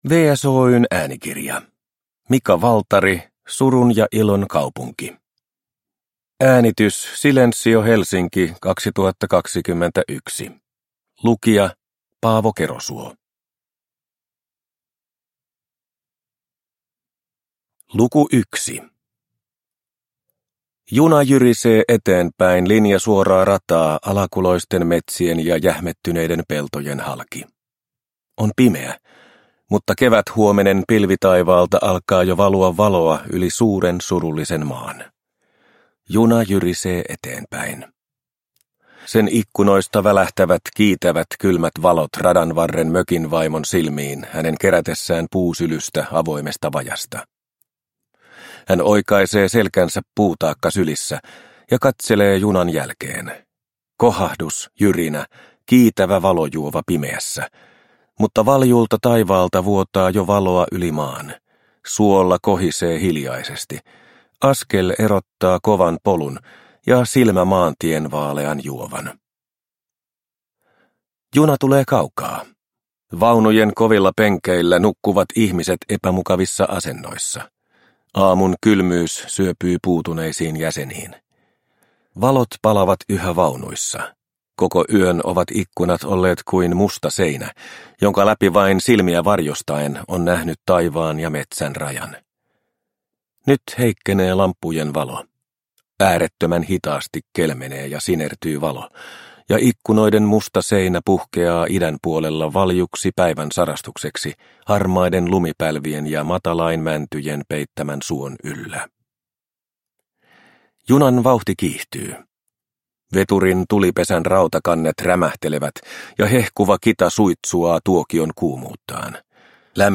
Surun ja ilon kaupunki – Ljudbok – Laddas ner